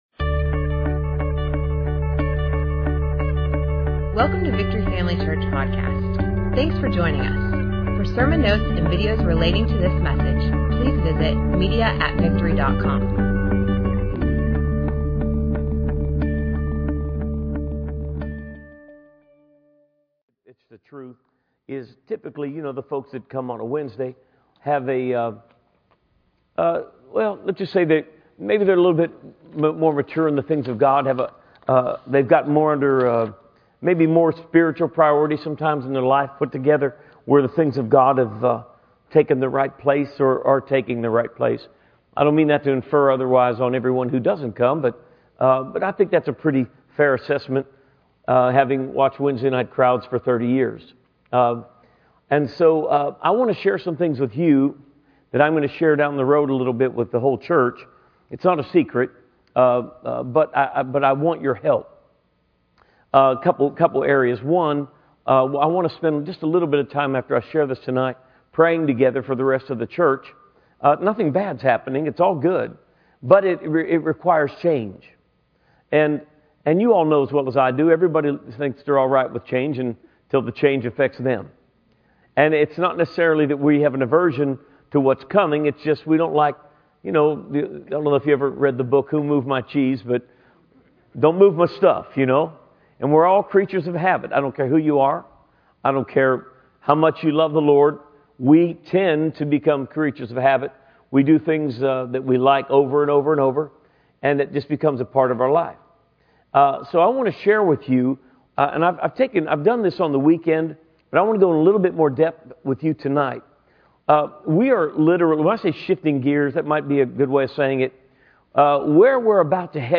Access sermon video, audio, and notes from Victory Family Church online today!